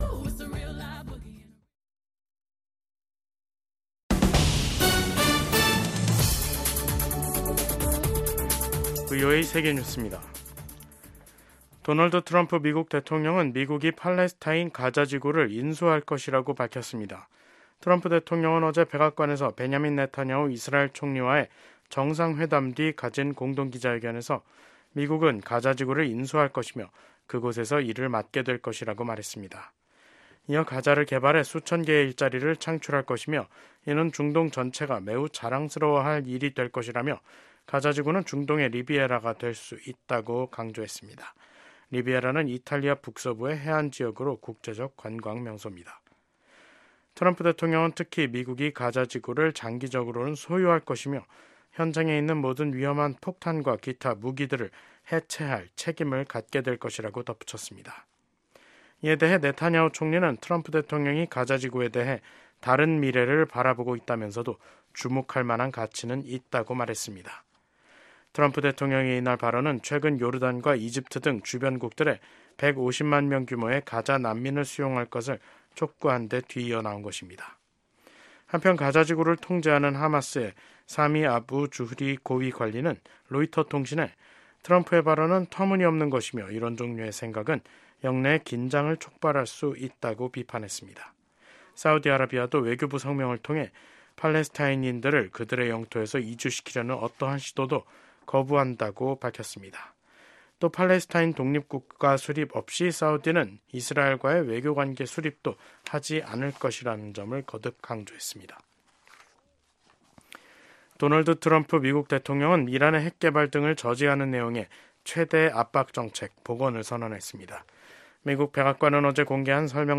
VOA 한국어 간판 뉴스 프로그램 '뉴스 투데이', 2025년 2월 5일 3부 방송입니다. 러시아에 파견돼 상당수 사상자가 발생한 북한 군이 쿠르스크 전선에서 최근 일시 퇴각했다는 소식이 전해지면서 추가 파병이 임박한 게 아니냐는 관측이 나오고 있습니다. 북대서양조약기구(나토. NATO)가 북한의 러시아 파병 증원설과 관련해 양국에 국제법 위반 행위를 즉각 중단할 것을 촉구했습니다.